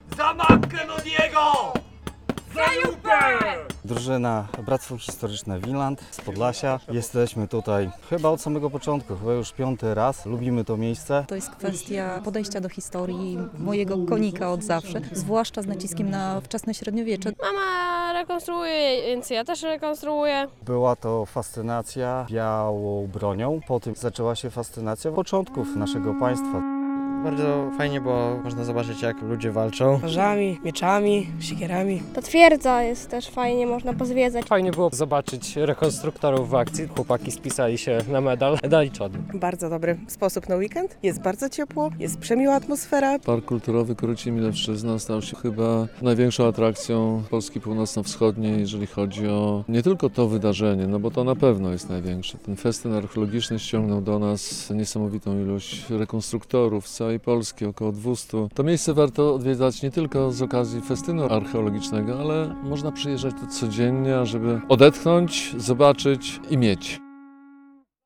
Festyn Archeologiczny w Parku Kulturowym Korycin-Milewszczyzna - relacja